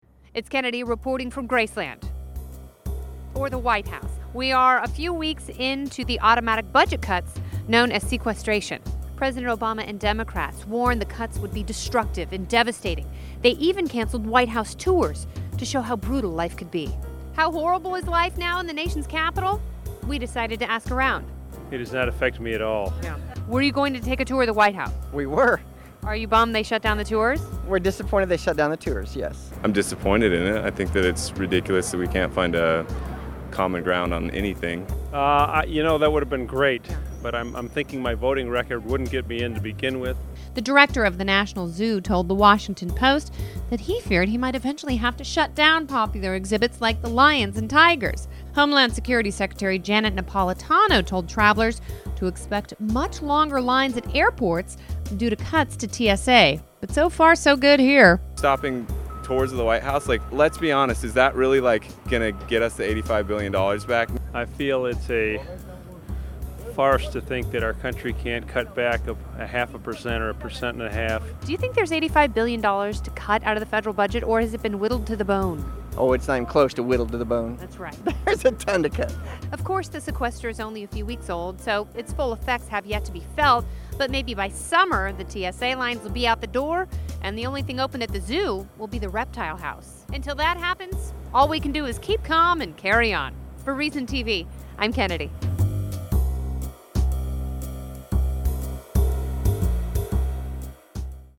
It's been over a month since the automatic federal spending cuts known as "the sequester" kicked in and Reason TV's Kennedy reports from the suddenly-mean streets of Washington, D.C.